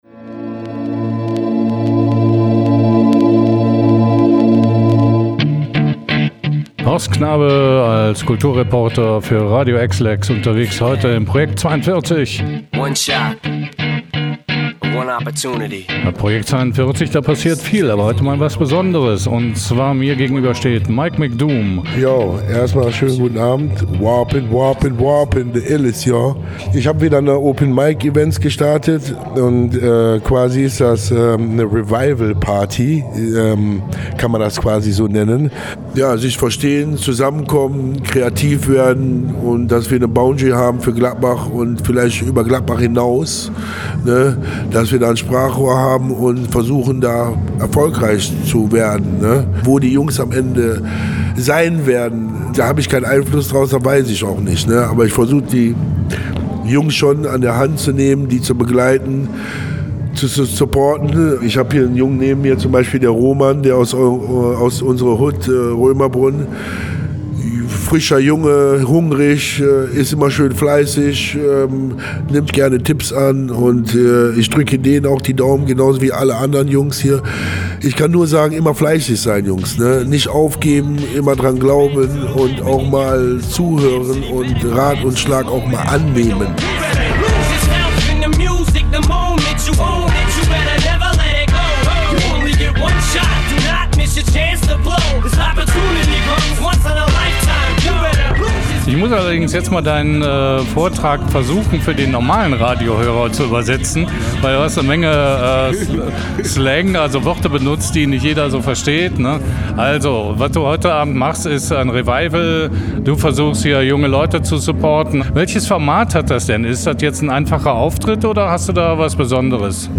Interview-Open-Mic-Session-HK-TB-1.mp3